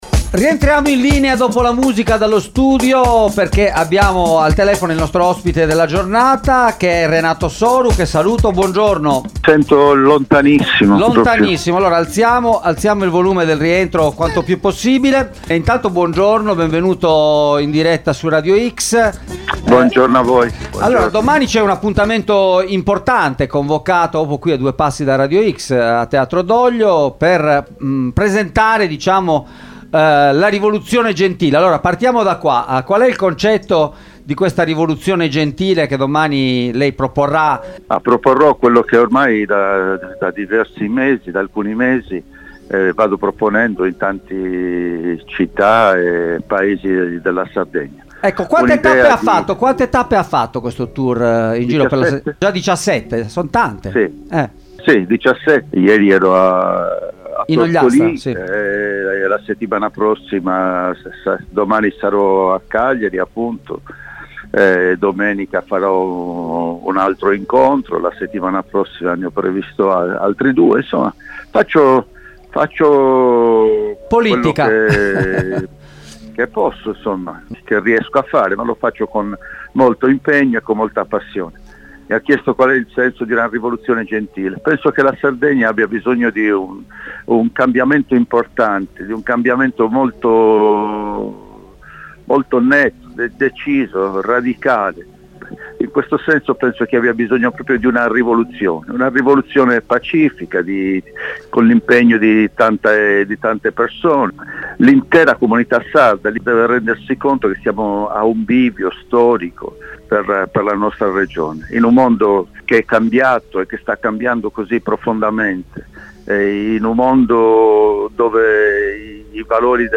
A poche ore dall’annuncio, pubblicato sui propri canali social, di una grande assemblea pubblica in cui sarà presentata la “Rivoluzione gentile”, Renato Soru è intervenuto ai microfoni di Radio X per fare il punto sulla situazione politica del centrosinistra in Sardegna e raccontare le motivazioni che hanno portato l’ex presidente della Regione a tornare in campo per le elezioni del 2024.